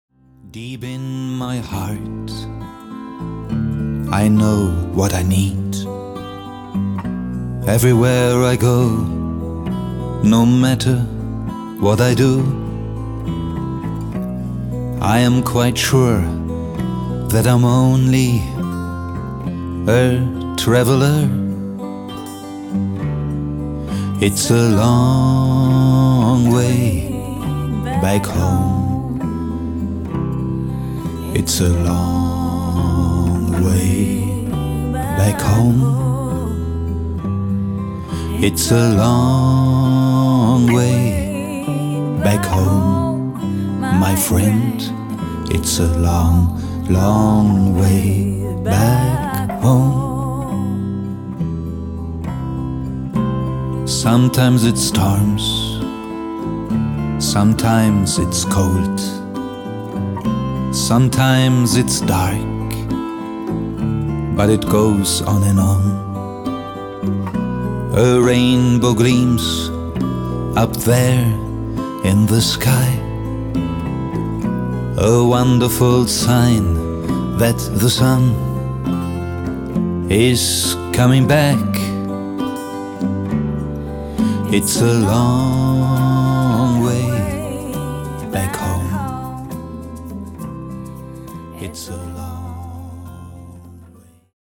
Der Soundtrack
Es sind sowohl Instrumental- als auch Gesangsstücke.